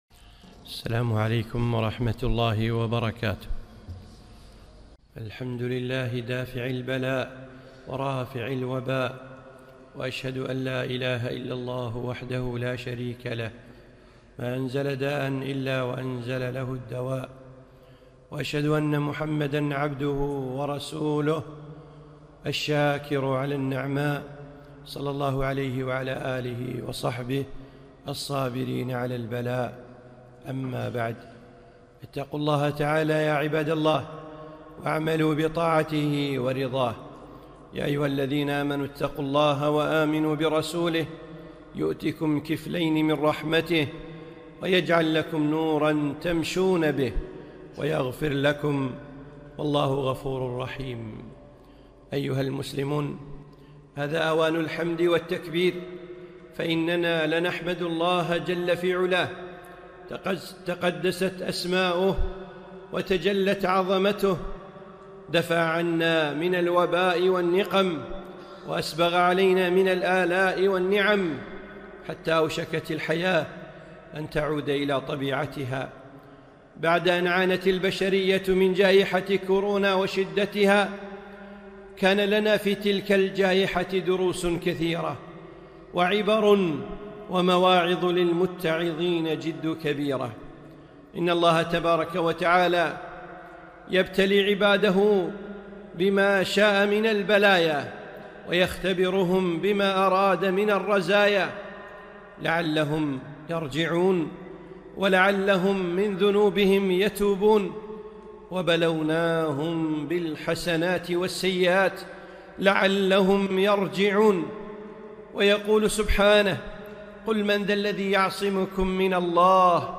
خطبة - دروس من كورونا